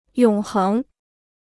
永恒 (yǒng héng) Free Chinese Dictionary